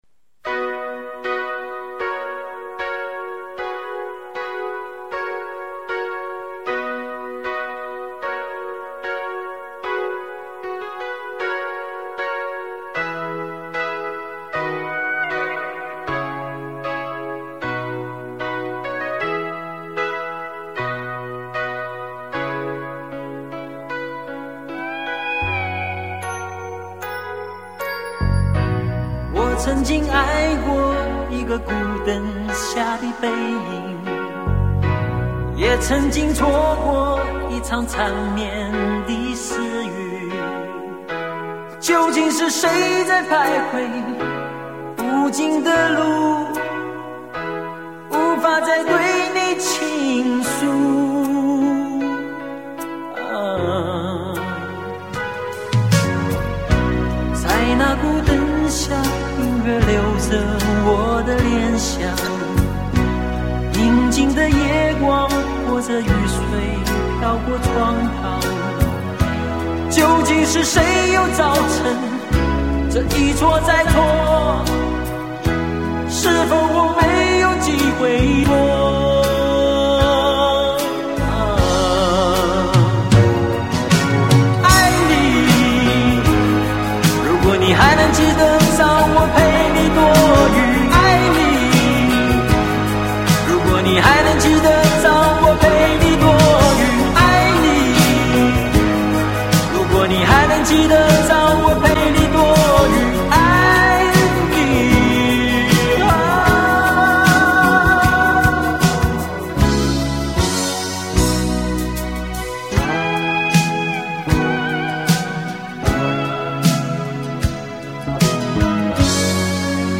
黑胶LP
悦耳流畅的钢琴前奏很容易的让人回到初恋的年代。